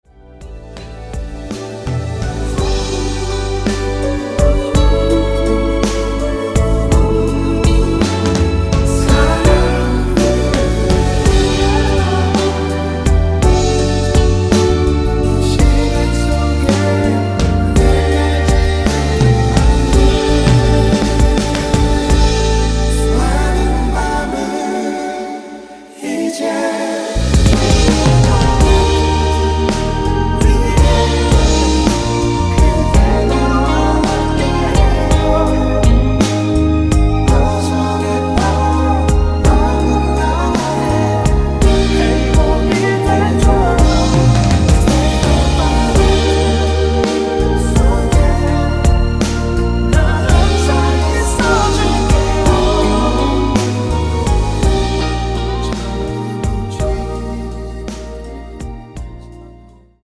원키 멜로디와 코러스 포함된 MR입니다.(미리듣기 참조)
앞부분30초, 뒷부분30초씩 편집해서 올려 드리고 있습니다.
중간에 음이 끈어지고 다시 나오는 이유는